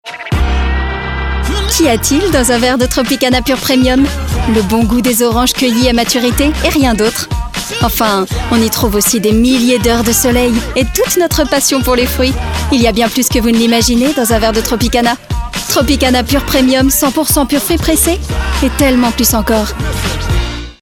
Voix off pub TV